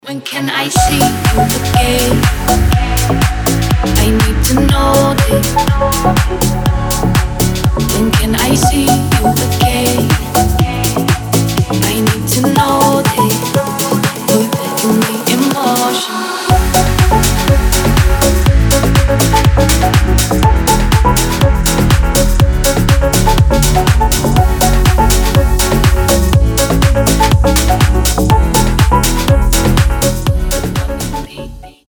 • Качество: 320, Stereo
женский вокал
remix
deep house
Приятный дип-хаус от трех исполнителей из разных стран.